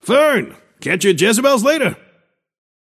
Shopkeeper voice line - Fern! Catch you at Jezebel’s later?